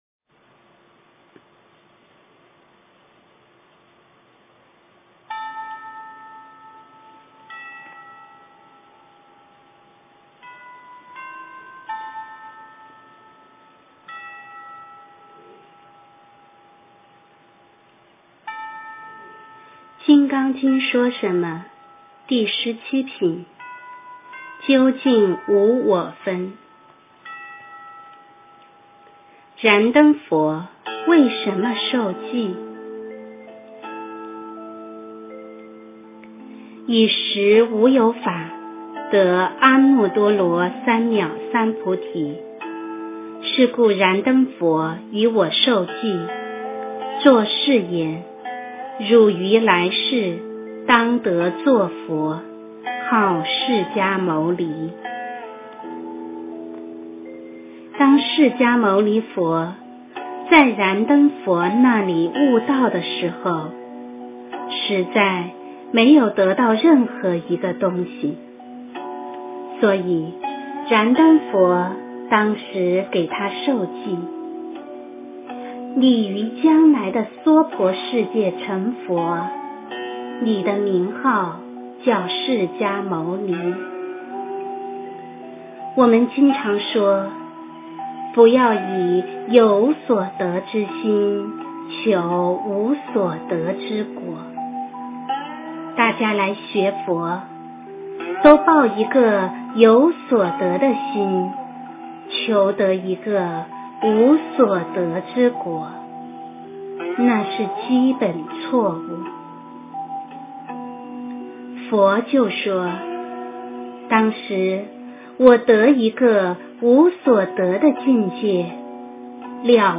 第十七品然灯佛为什么授记 - 诵经 - 云佛论坛